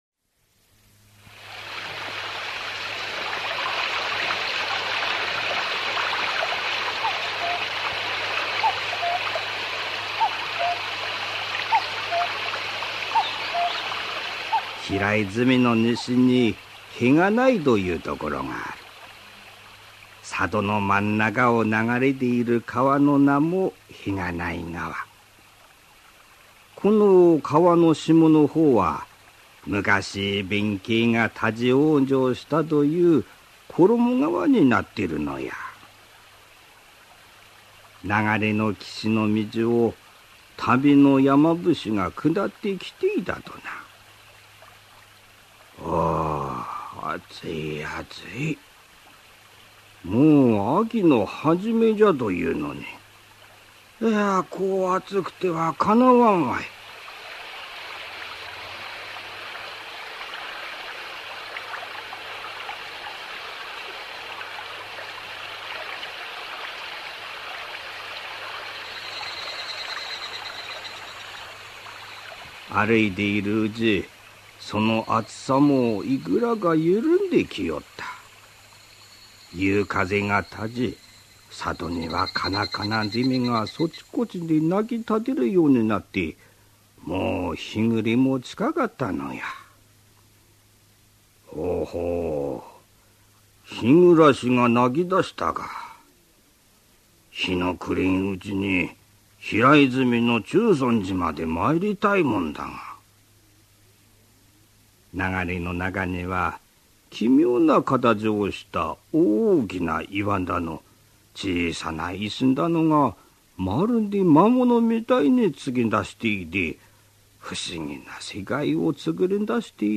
[オーディオブック] 石になった山伏